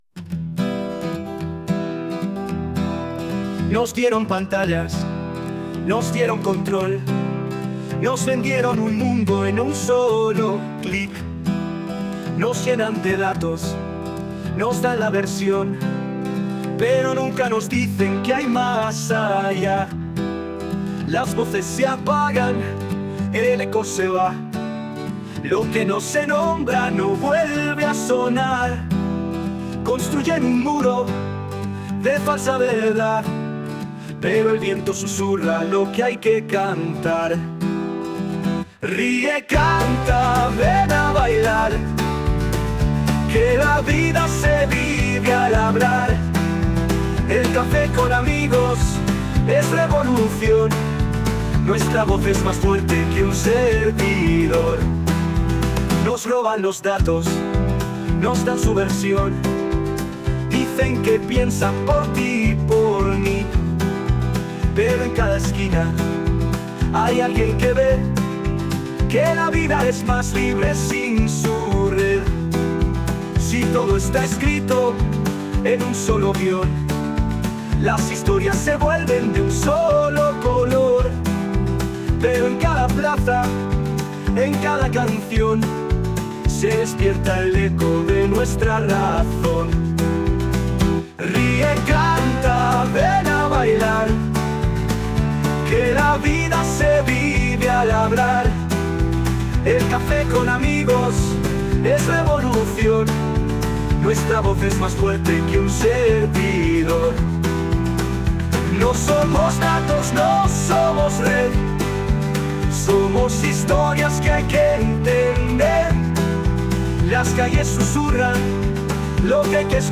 El hombre comenzó con los acordes, y la guitarra cobró vida con energía, abriendo paso a una voz melódica y tranquila, pero llena de fuerza.